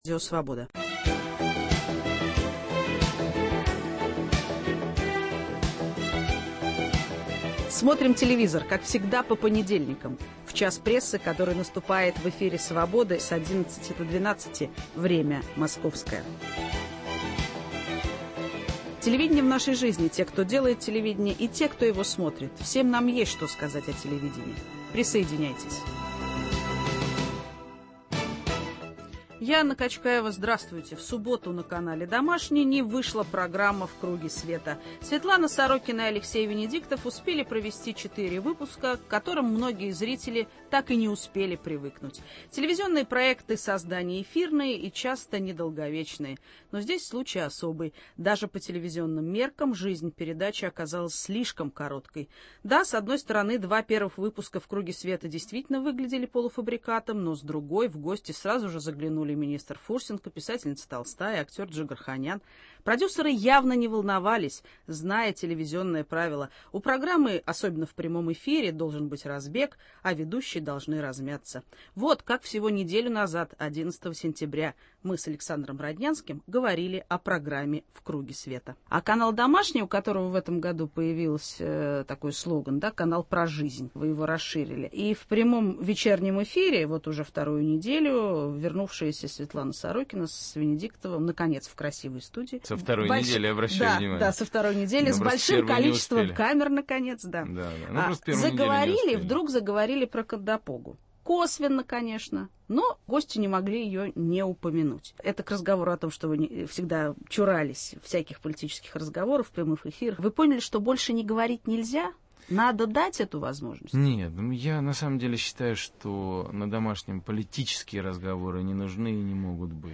О причудливости судеб телевизионных программ - разговор со Светланой Сорокиной.